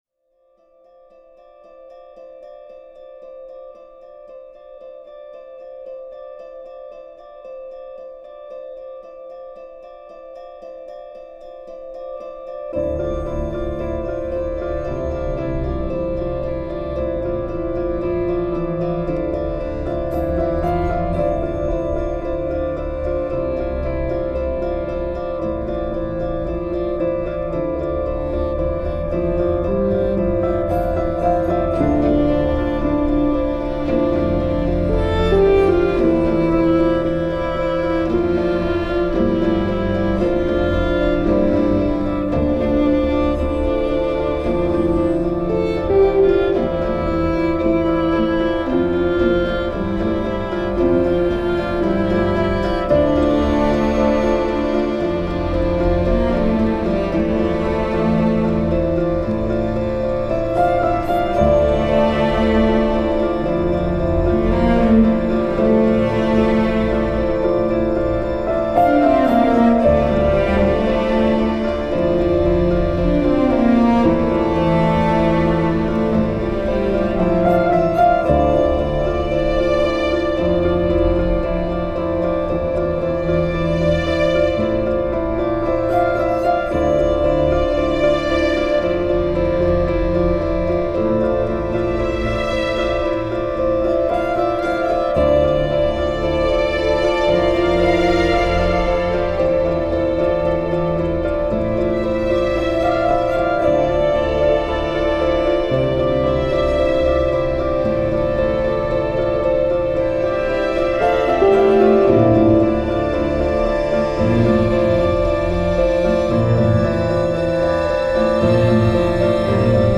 US • Genre: Soundtrack